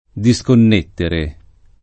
disconnettere [diSkonn$ttere o